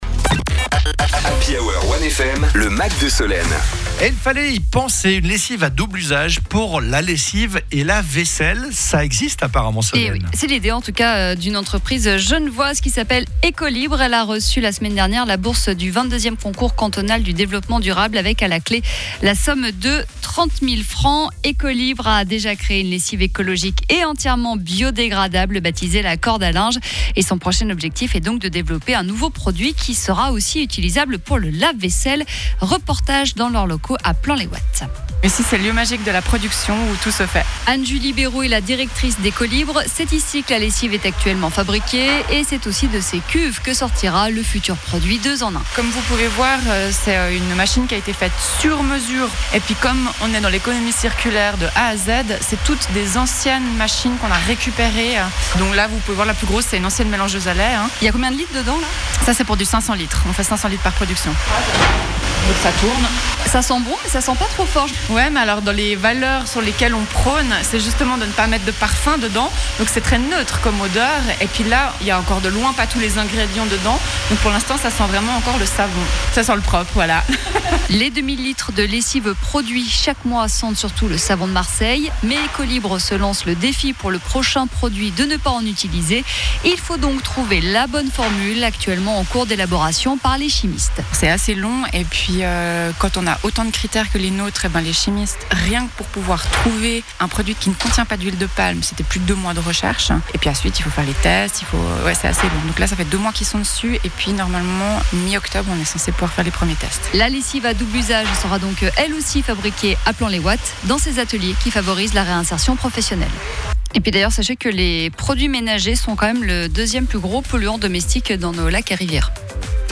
Reportage dans leur locaux à Plan-les-Ouates: